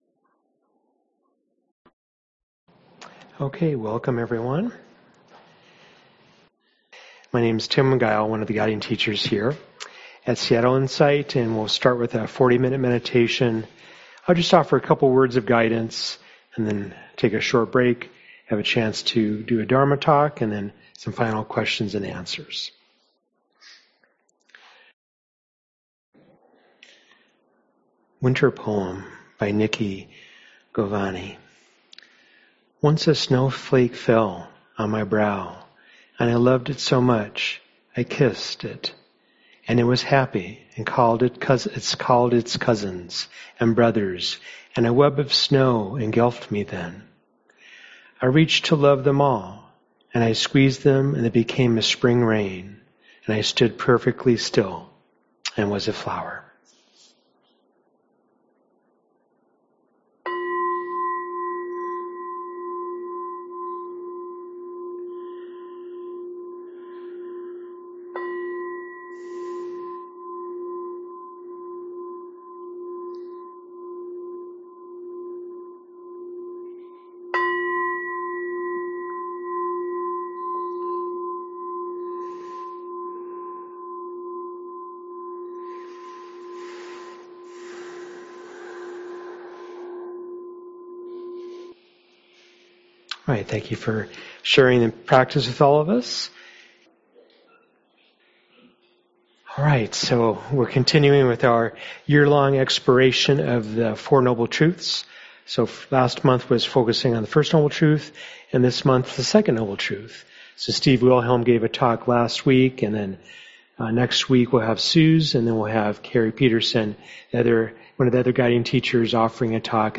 Audio recordings of Buddhist teachings and discussions with local and visiting teachers of the Dhamma.